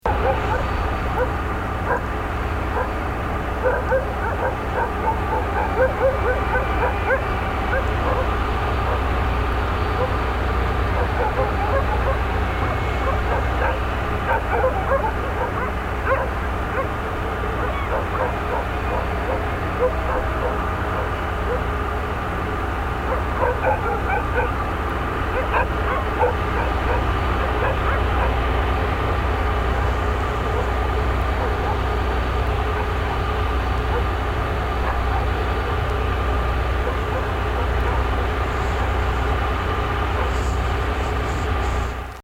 63 Generator:   00:42m
Sound of diesel-powered electricity generator.